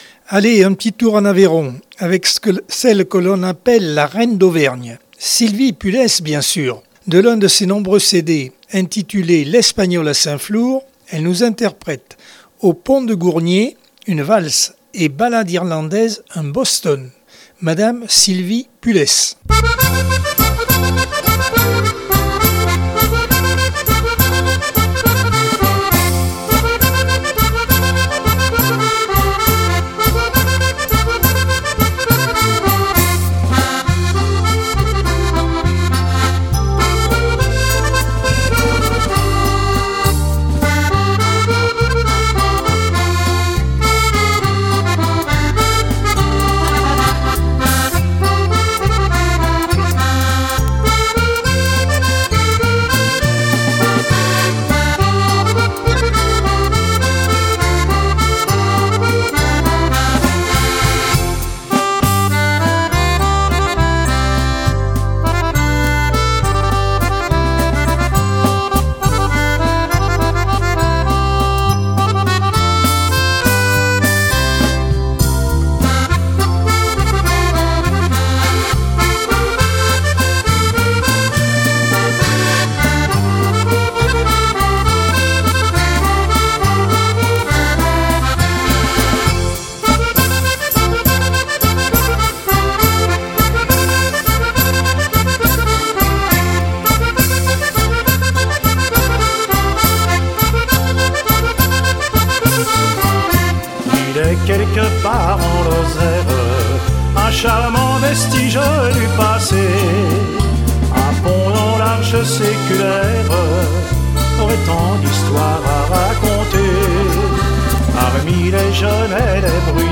Accordeon 2022 sem 03 bloc 2 - Radio ACX